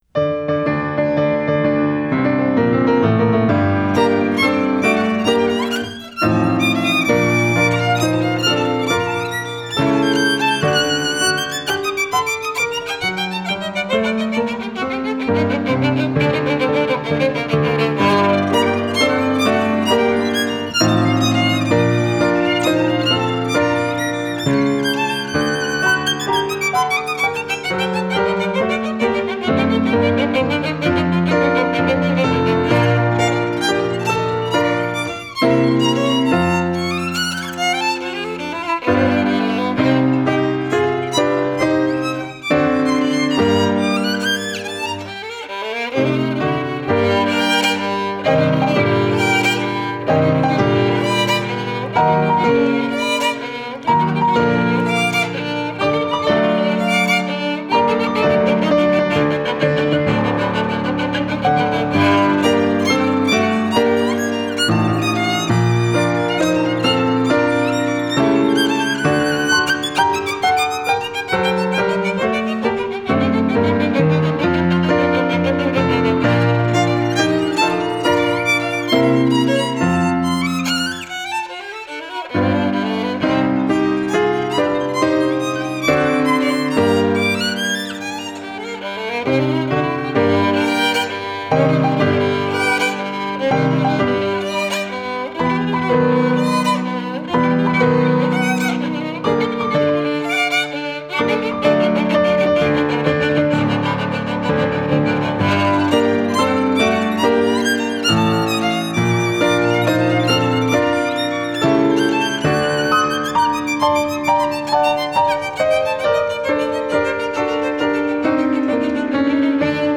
Producción de un CD con las obras interpretadas por los estudiantes de recitales, ensamble de jazz, música ecuatoriana y música Latinoamericana de la Universidad de Los Hemisferios del semestre 2017-1 de mayor calidad sonora y mejor ejecución musical